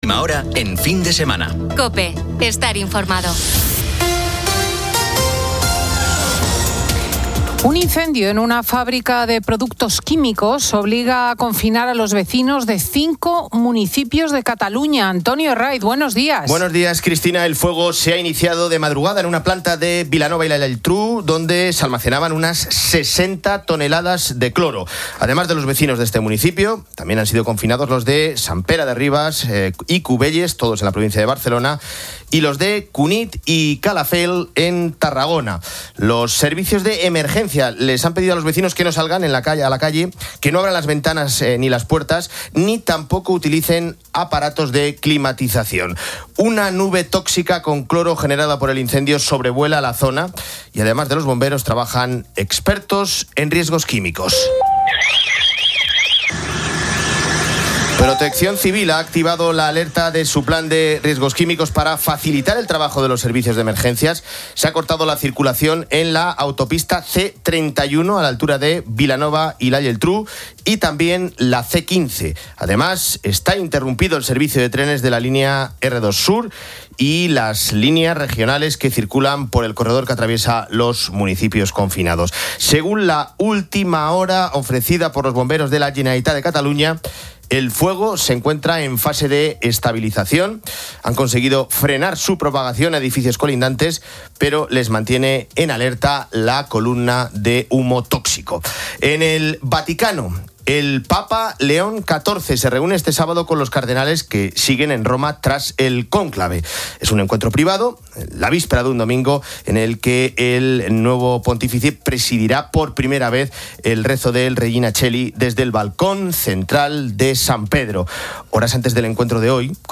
Fin de Semana 10:00H | 10 MAY 2025 | Fin de Semana Editorial de Cristina López Schlichting.